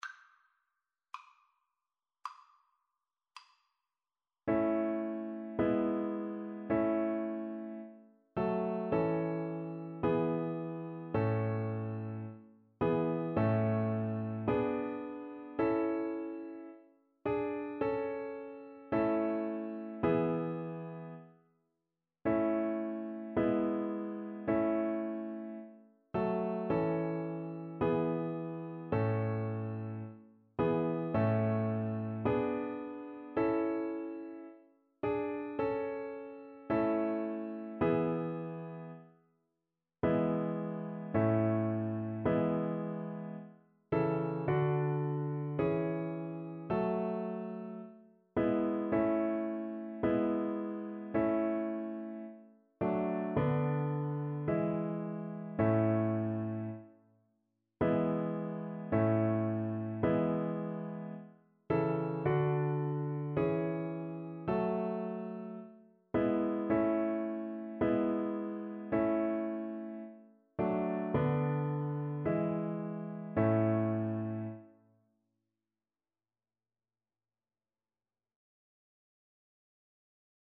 Play (or use space bar on your keyboard) Pause Music Playalong - Piano Accompaniment Playalong Band Accompaniment not yet available transpose reset tempo print settings full screen
A major (Sounding Pitch) (View more A major Music for Violin )
4/4 (View more 4/4 Music)
Classical (View more Classical Violin Music)